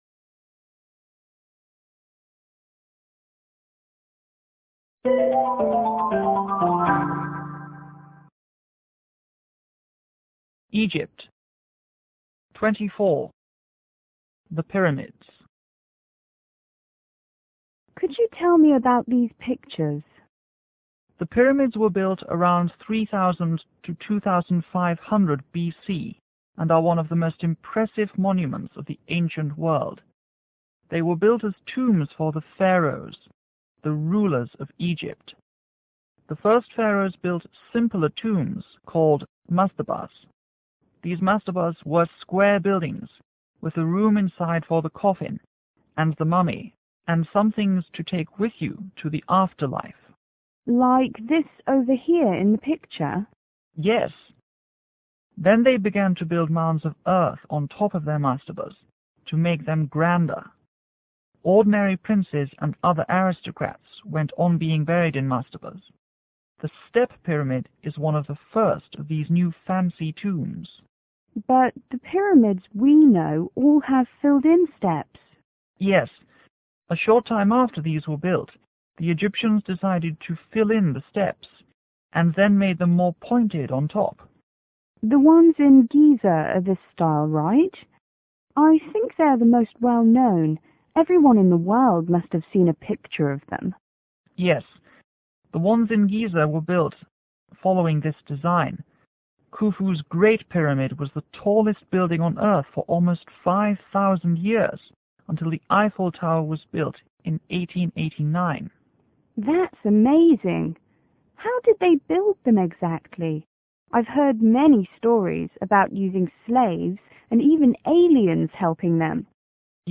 T: Traveller      G:Tour guide